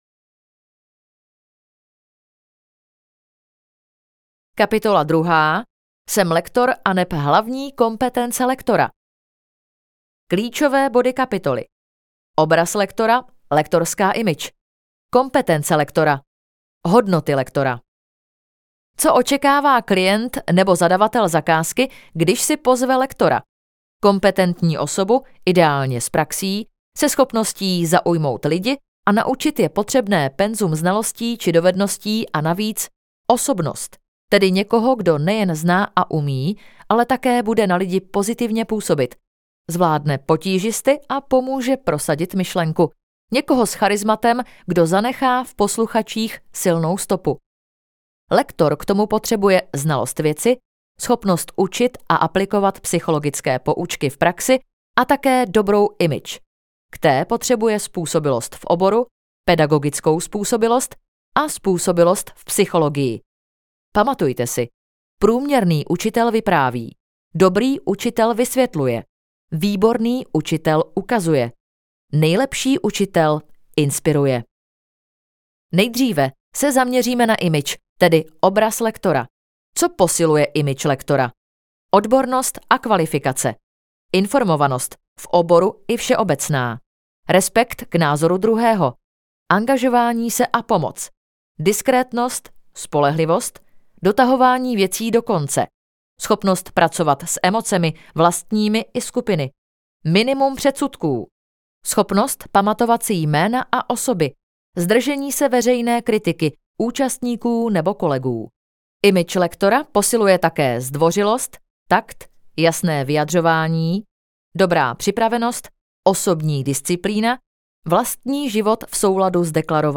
Audiokniha Lektorské dovednosti - Olga Medlíková | ProgresGuru